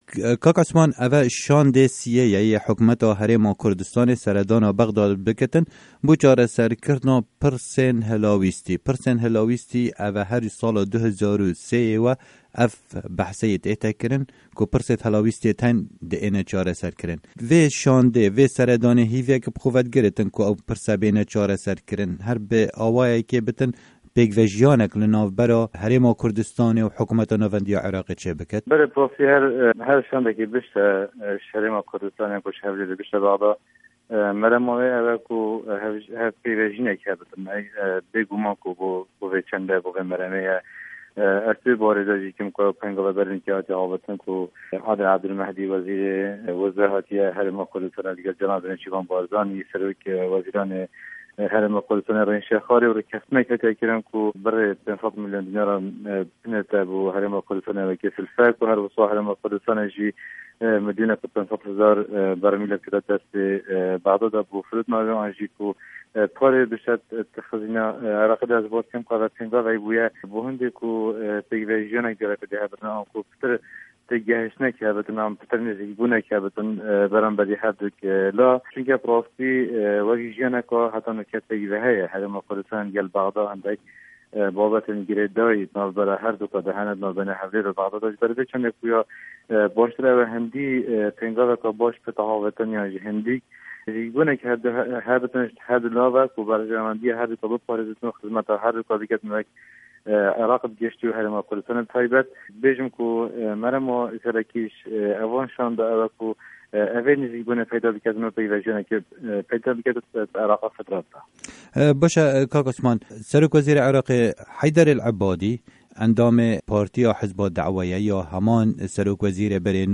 Endamê Kurd li Parlemana Îraqî Osman Tahîr Hemzanî Doskî di hevpeyvînekê de ligel Dengê Amerîka dibêje, kombûn û dîyalog ligel hikûmeta Îraqê herdem karekî baş e bo bidestvexistina berjewendîyan.
hevpeyvin digel Osman Doskî